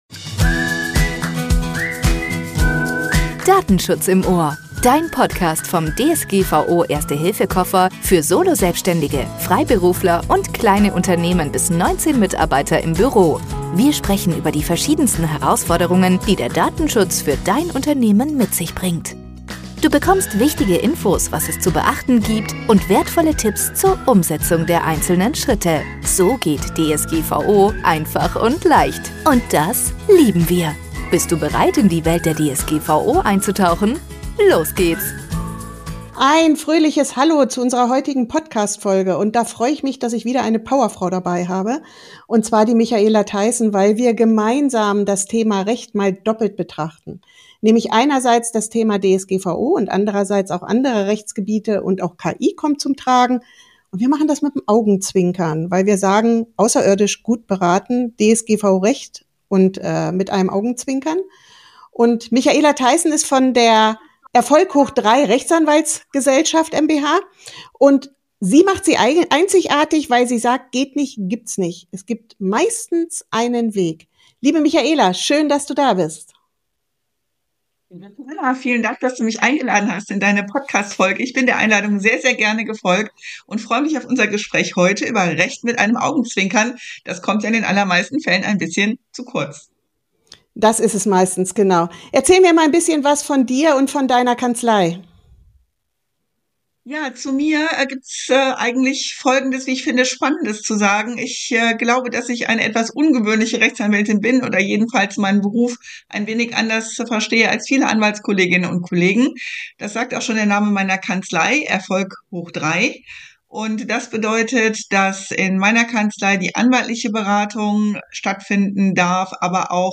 Hör unbedingt rein, in eine Folge zwischen Paragrafen, Praxis und jeder Menge Humor.